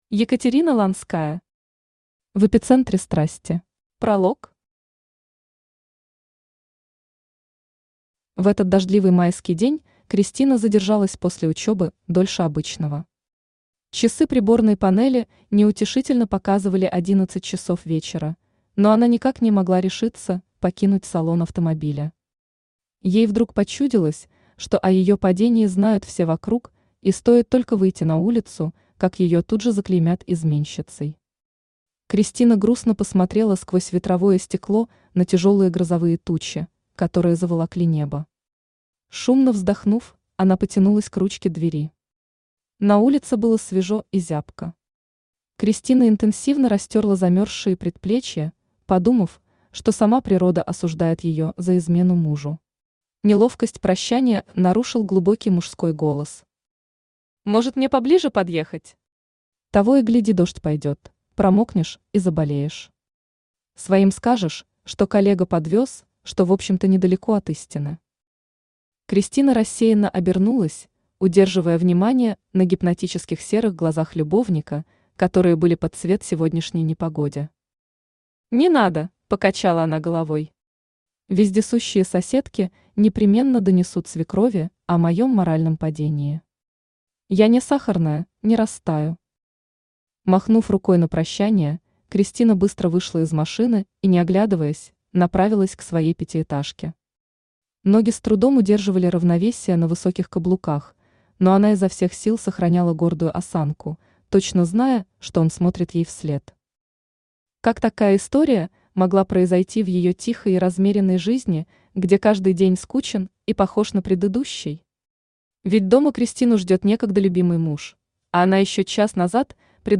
Аудиокнига В эпицентре страсти | Библиотека аудиокниг
Aудиокнига В эпицентре страсти Автор Екатерина Ланская Читает аудиокнигу Авточтец ЛитРес.